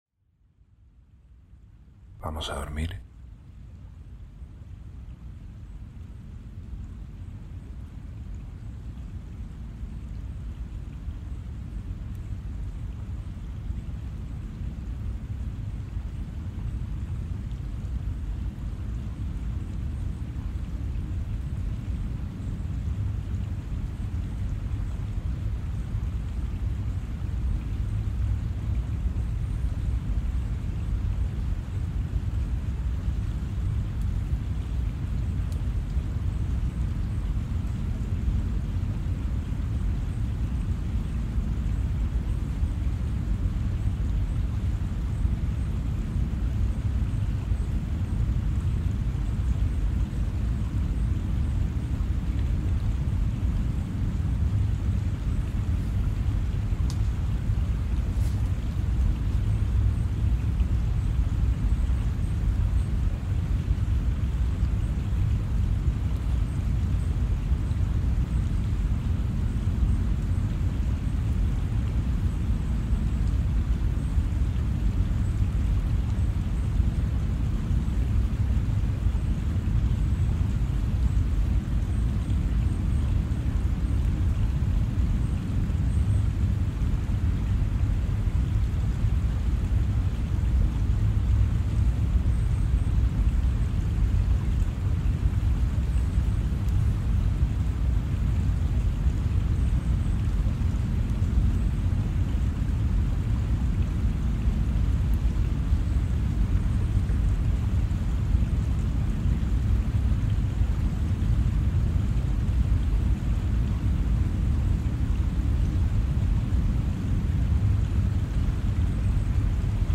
Ambientes para Dormir ✨ Ventisca en la Montaña ❄
Experiencias inmersivas para ayudarte a dormir profundamente.